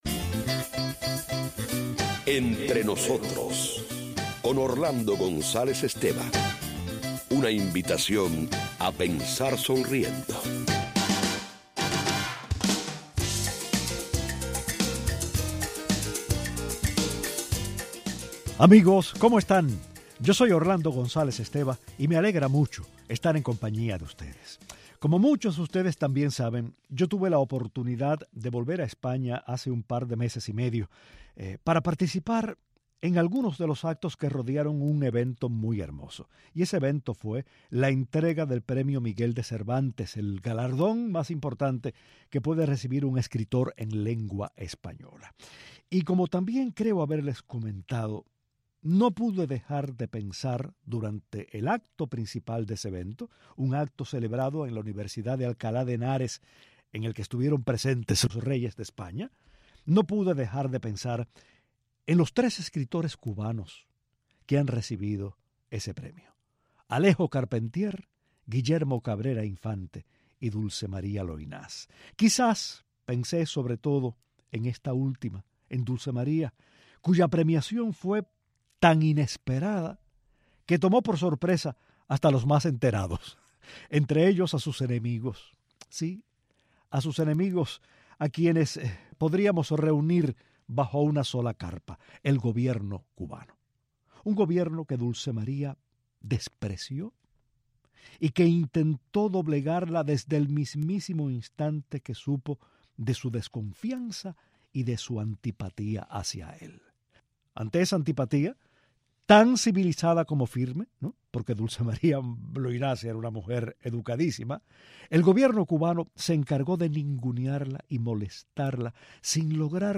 Hoy recordamos a Dulce María Loynaz, su oposición al gobierno cubano, el hostigamiento y el aislamiento de que fue víctima y los testimonios de dos hombres que siendo niños fueron alentados por el presidente del Comite de Defensa de su barrio a arrojar piedras contra la casa de la escritora.